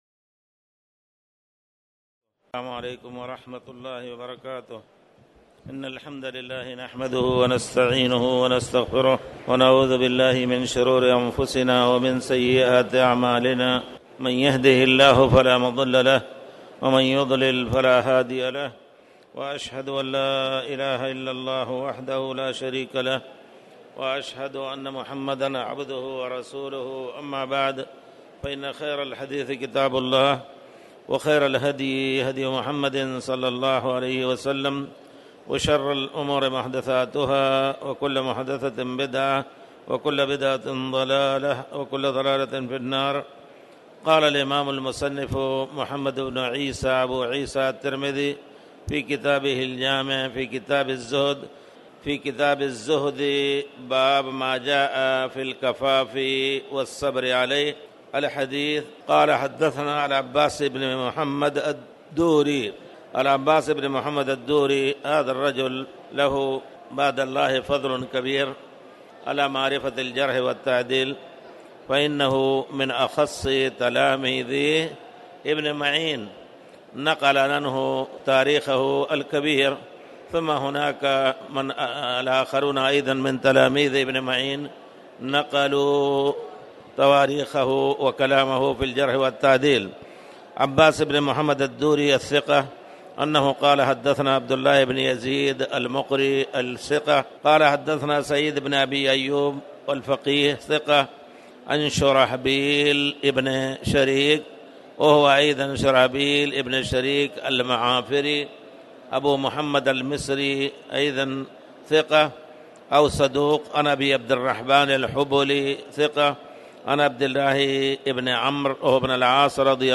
تاريخ النشر ١٥ جمادى الأولى ١٤٣٩ هـ المكان: المسجد الحرام الشيخ